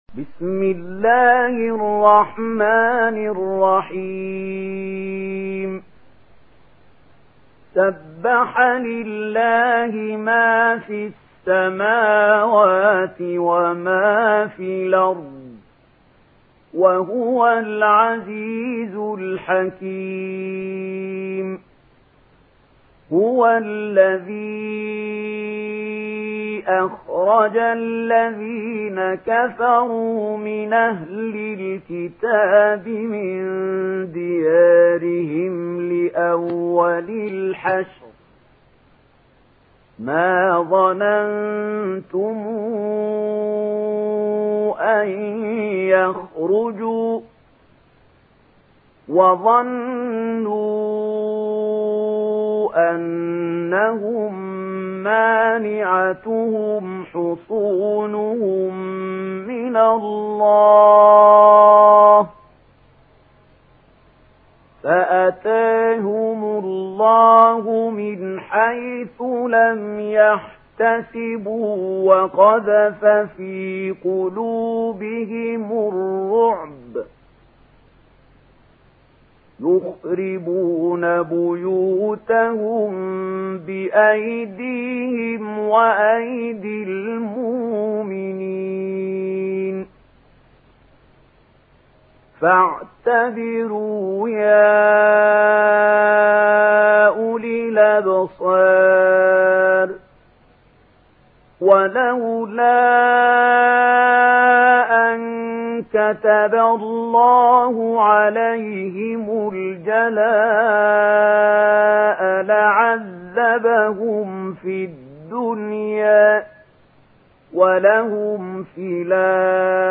سورة الحشر MP3 بصوت محمود خليل الحصري برواية ورش
مرتل ورش عن نافع